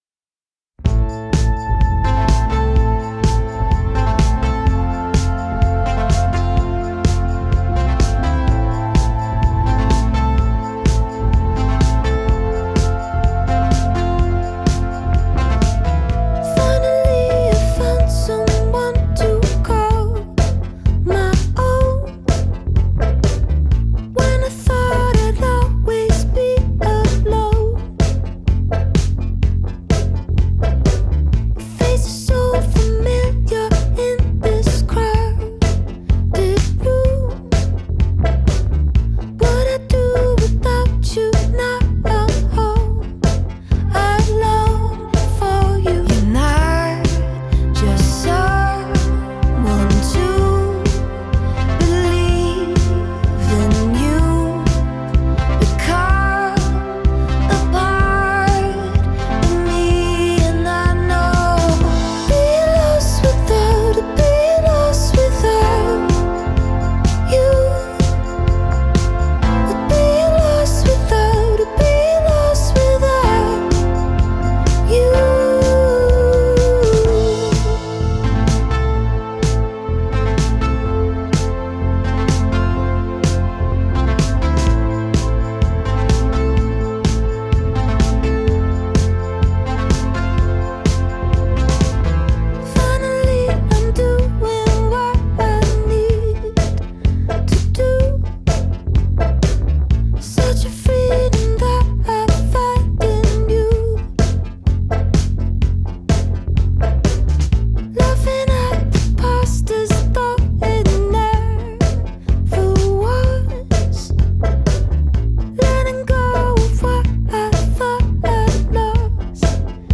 Жанр: pop · female vocalists · indie · singer-songwriter